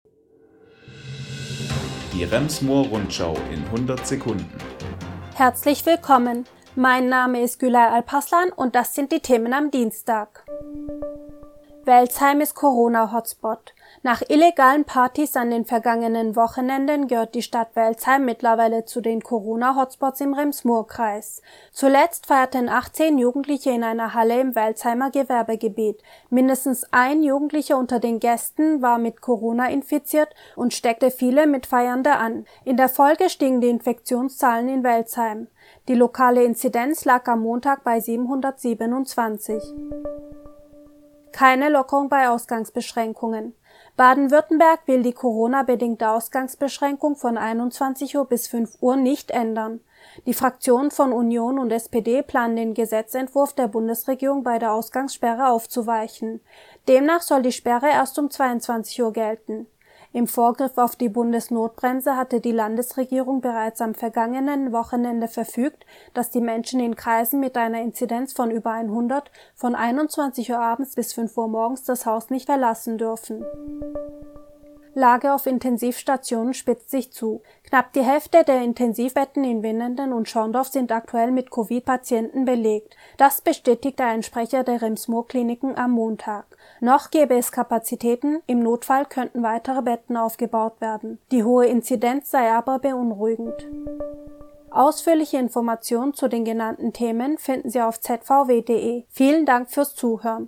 Die wichtigsten Nachrichten des Tages